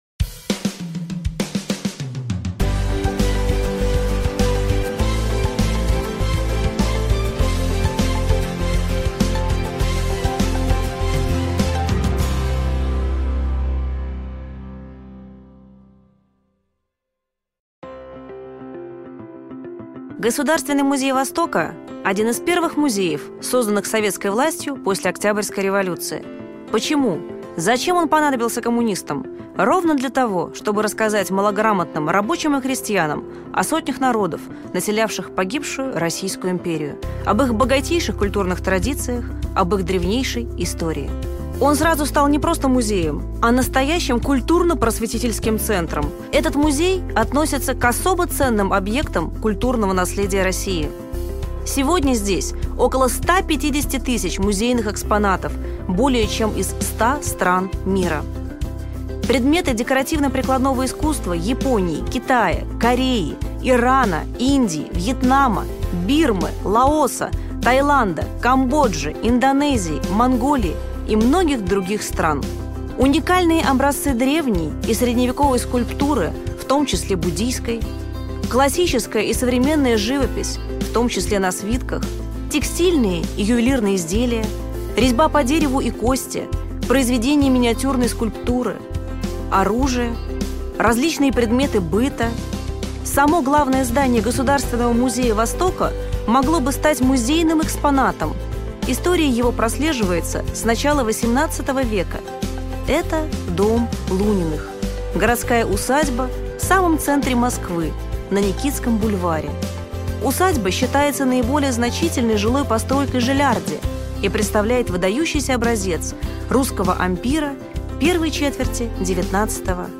Аудиокнига Образ самурая в японской культуре: путь от варвара до идеала | Библиотека аудиокниг